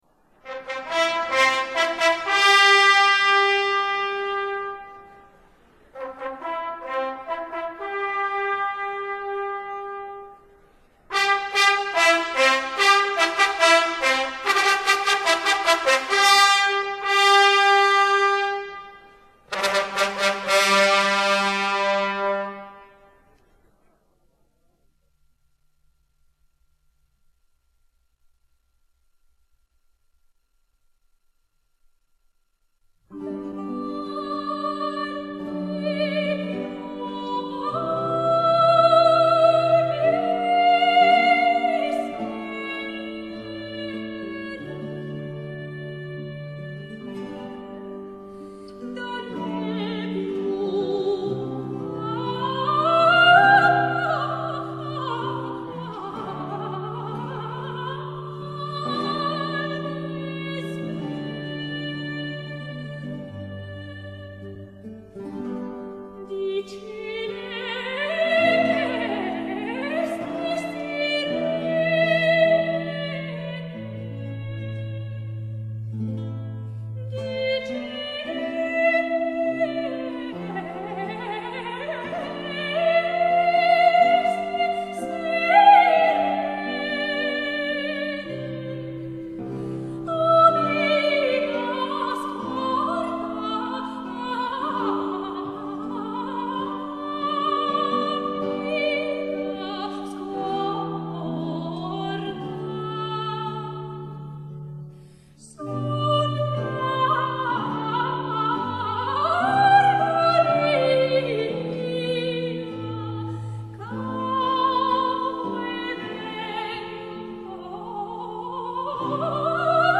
Intermedio
A theatrical performance or spectacle with music and often dance which was performed between the acts of a play to celebrate special occasions in Italian courts.